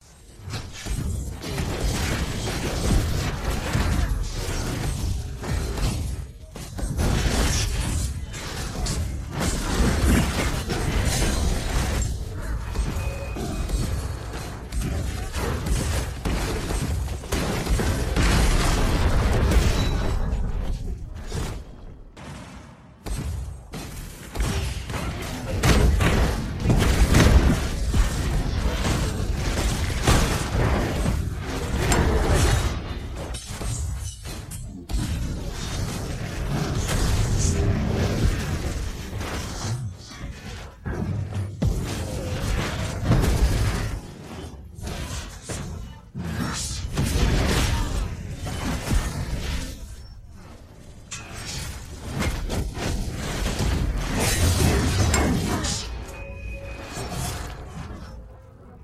Tiếng đánh Liên Quân, chơi game Liên Quân mobile
Thể loại: Tiếng đồ công nghệ
Description: Âm thanh va chạm, SFX kỹ năng, tiếng chém, tiếng nổ trong giao tranh, tiếng tung chiêu, kích hoạt ulti, last hit, combo, phá trụ, cùng nhạc nền sôi động... là linh hồn trải nghiệm chơi game Liên Quân Mobile...
tieng-danh-lien-quan-choi-game-lien-quan-mobile-www_tiengdong_com.mp3